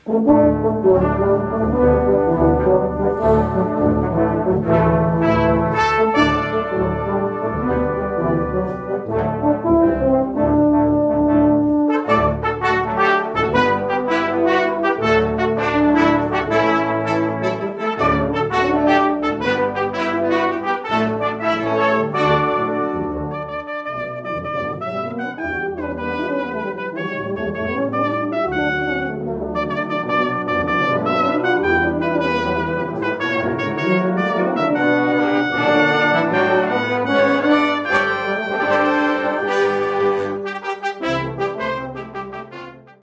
Offene Probe | Brass Band Hallau
Probe_Muster.mp3